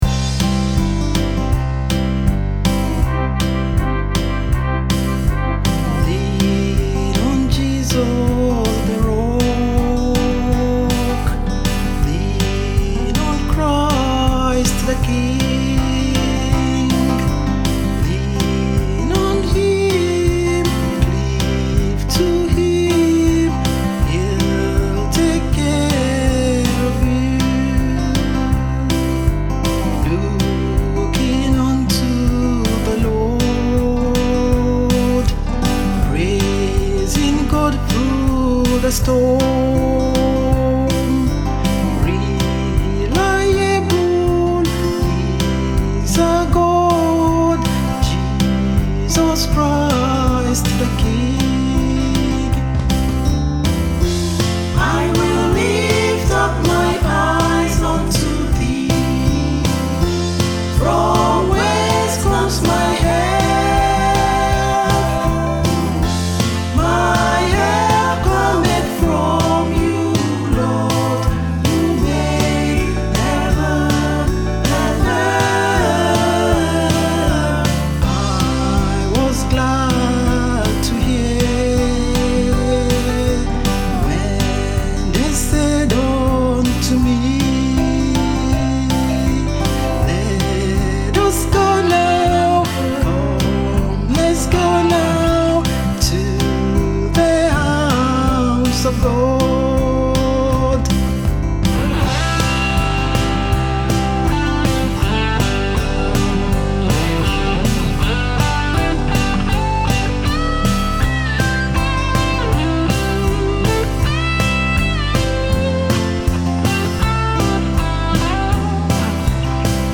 UK based singer, songwriter, and recording artiste